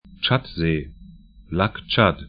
Tschadsee 'tʃat-ze: